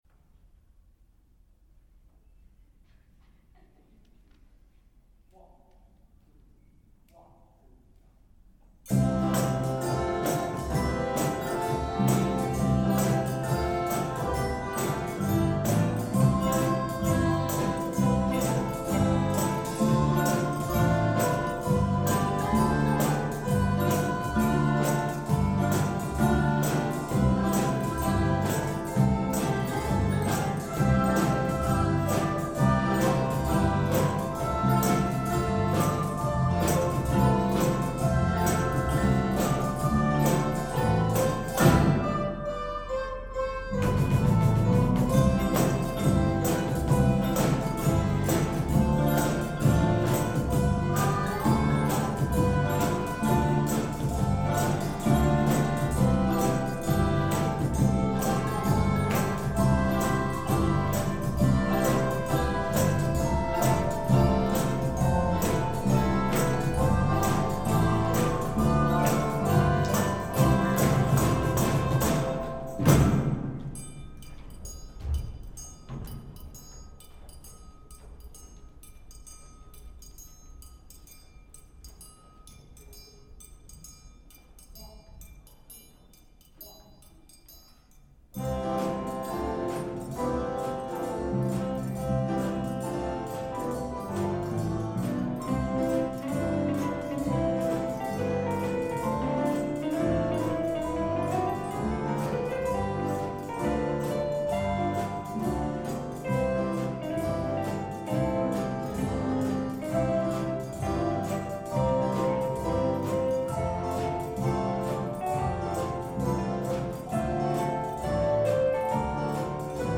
鍵盤ハーモニカの代わりに卓上ピアノやアコーディオンを使って呼気を出さないようにしたり、リコーダーは人数を減らし間隔を十分にあけて演奏したり、窓とドアを全開にして換気を行なったり、演奏の練習時間を短くしたりなどなど、様々な感染防止対策を講じながら行いました。
大人数になるため学年一緒に練習することはできなかったので、各クラスの演奏を順番につなぐ形で演奏しました。スタンダードの演奏の後にどのクラスもそのクラス独自のアレンジメントスタイルも演奏しています。３クラスともそれが違うのも聴きどころです。